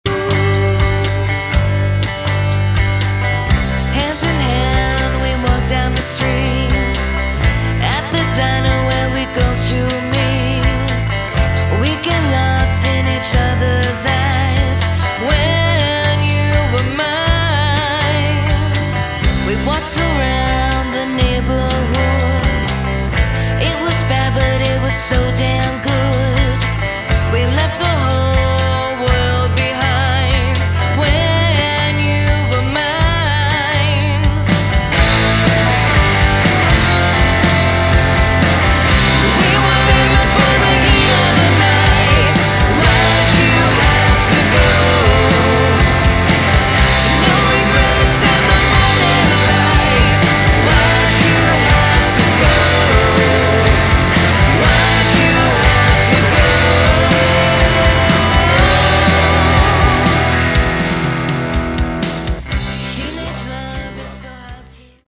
Category: Glam/Punk
vocals
guitar, backing vocals, piano
bass, backing vocals
drums, backing vocals